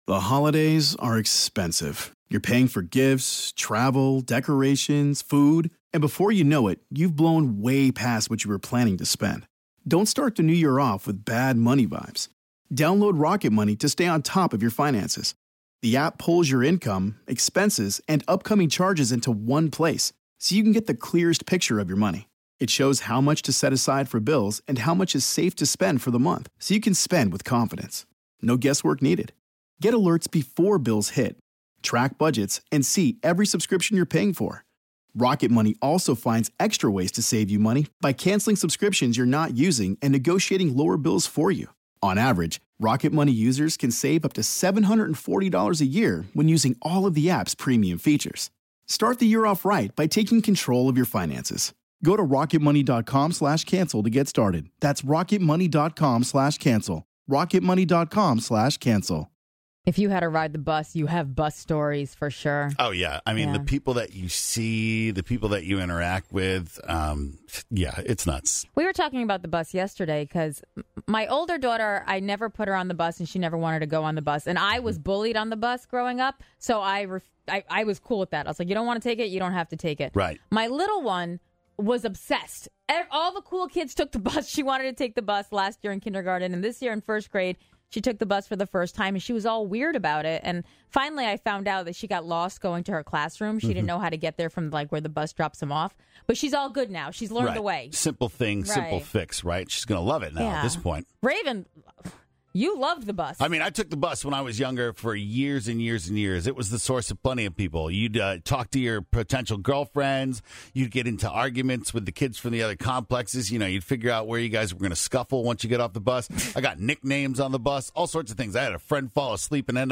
From being pulled over to going on tour, you have to listen to these calls.